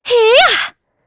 Isaiah-Vox_Attack2.wav